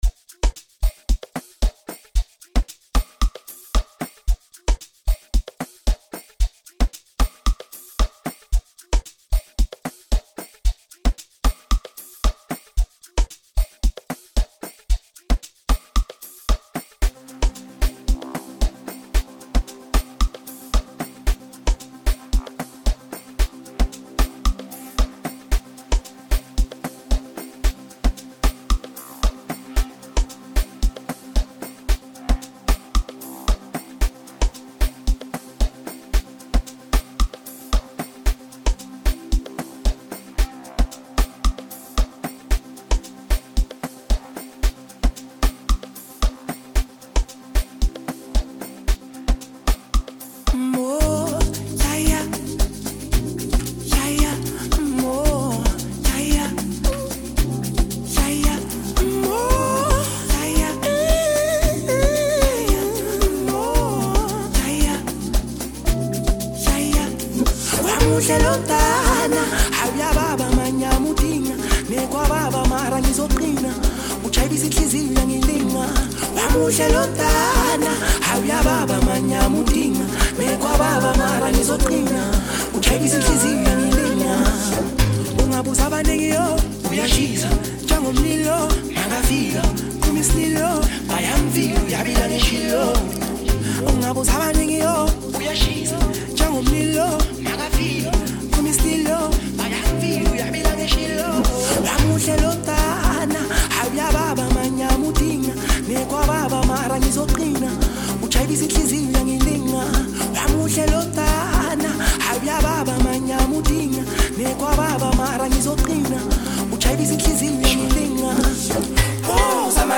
Amapiano-infused